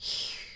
peach_snoring2.ogg